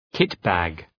Προφορά
{‘kıtbæg}